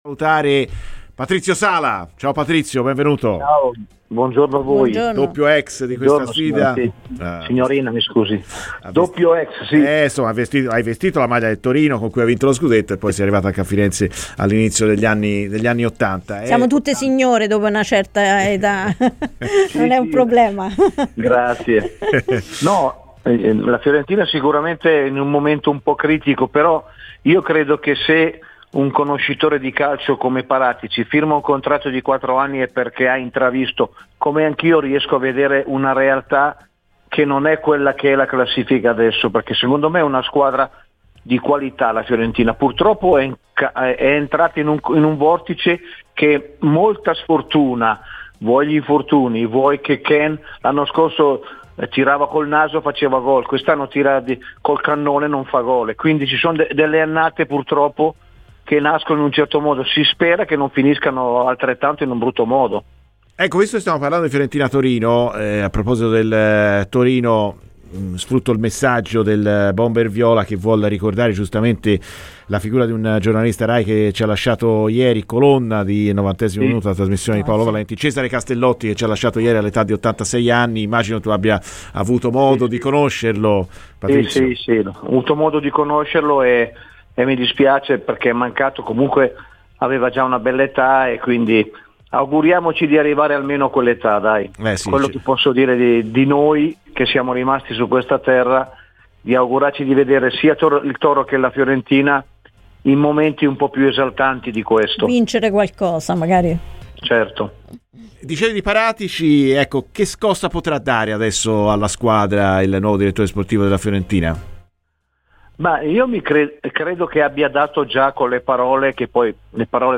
Patrizio Sala, ex giocatore di Torino e Fiorentina, ha rilasciato le seguenti parole a Radio FirenzeViola.